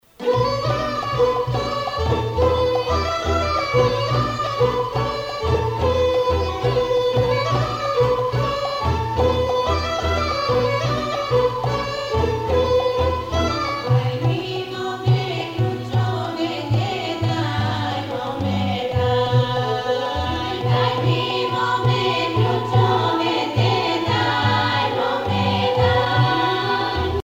Pièce musicale orchestrée n°13 avec choeur
Localisation Bulgarie